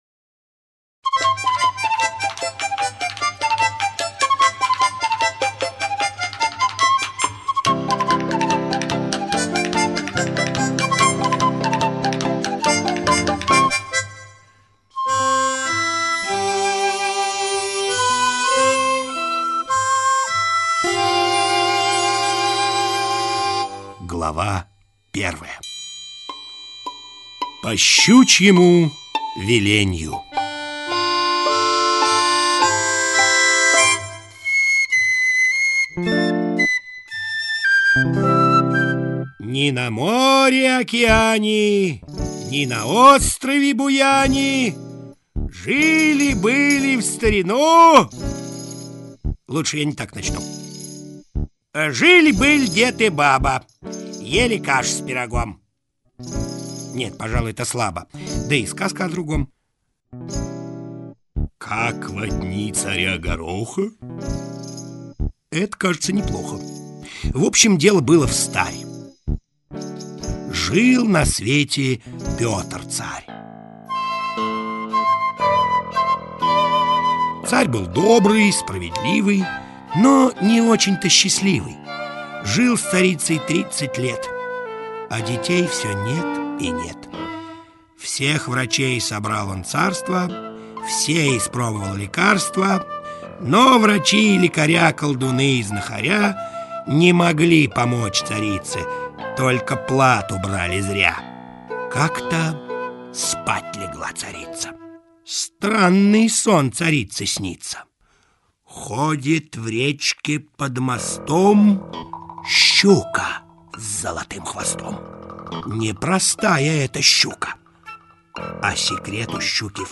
Иван-коровий сын - аудиосказка Усачёва - слушать онлайн